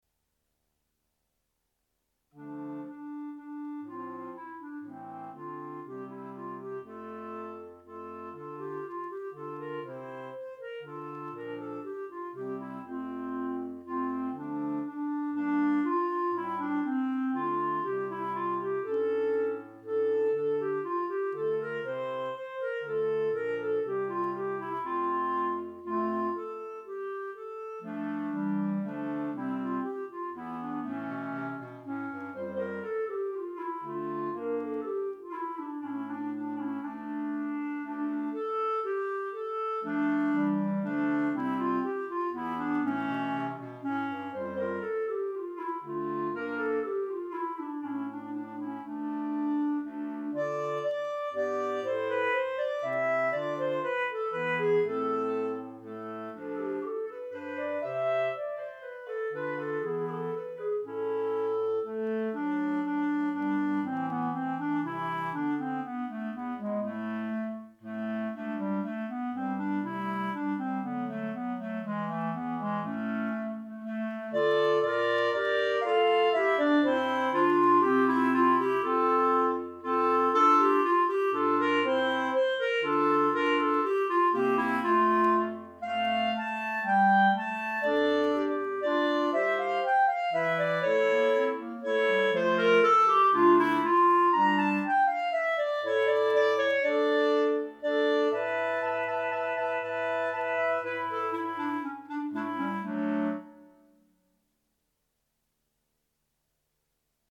Instrumentation:3 Clarinet, Bass Cl.